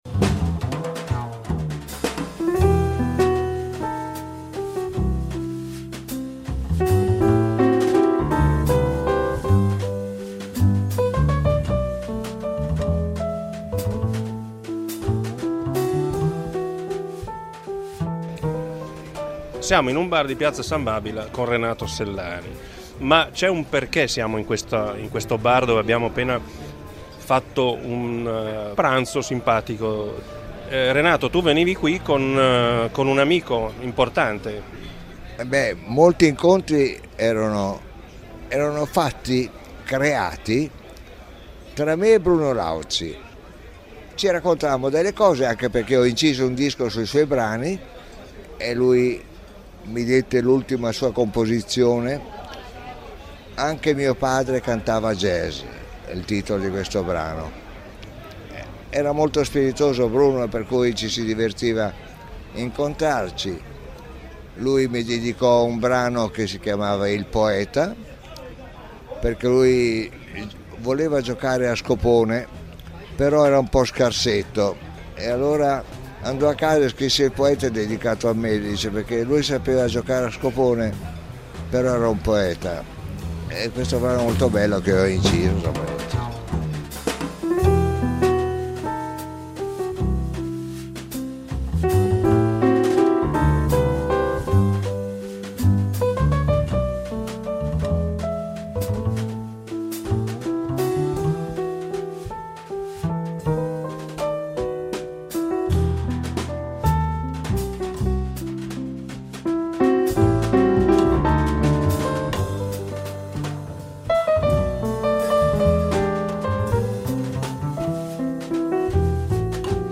Un incontro assai particolare con Renato Sellani quello chi vi proponiamo oggi: a settembre del 2010 ad un tavolino di un locale in piazza San Babila a Milano, a suo agio davanti ad un piatto di rigatoni al ragù, come alla tastiera di un pianoforte.